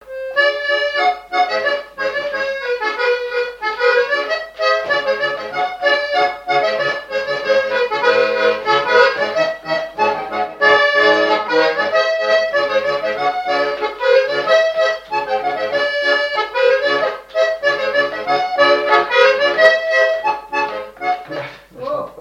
danse : scottich trois pas
Répertoire et souvenir des musiciens locaux
Pièce musicale inédite